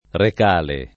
Recale [ rek # le ]